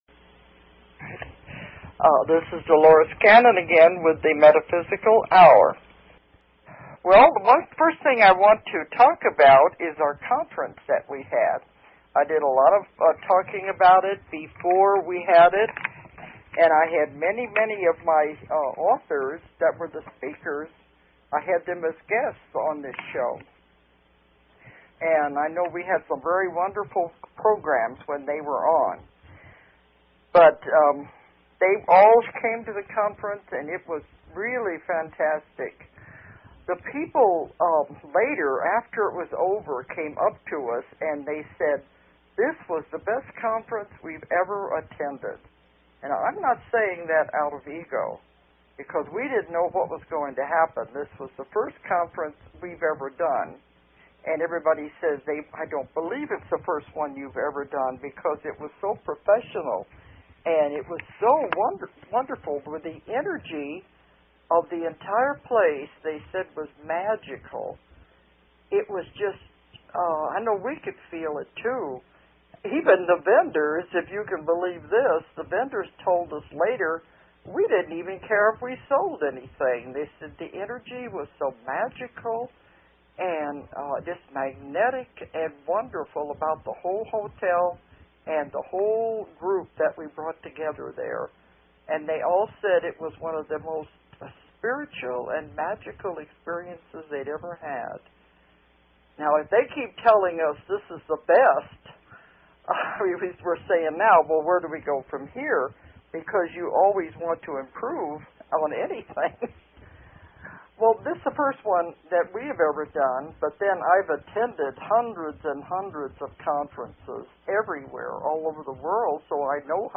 Fantastic authors who spoke at the first Transformation Conference in Fayetteville, AR